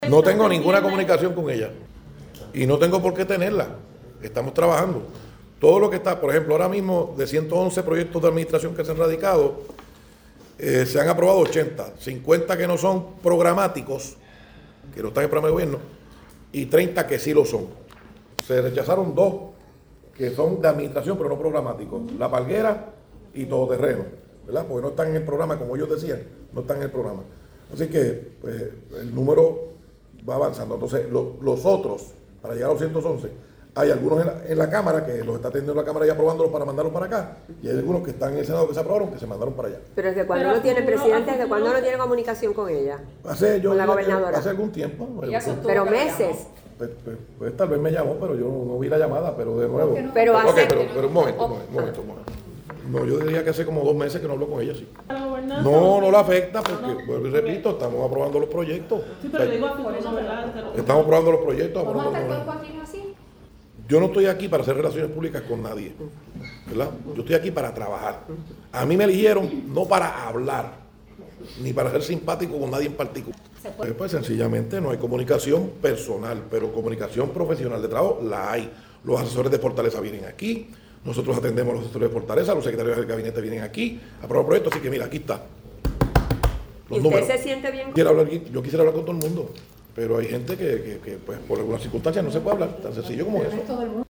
(Senado, 13 de abril de 2026)-Luego que el presidente del Senado Thomas Rivera Schatz, se ausentara de la reunión de la Conferencia Legislativa que llevo a cabo la gobernadora Jenniffer González Colón, a preguntas de la prensa de cómo estaba la comunicación con la gobernadora dijo: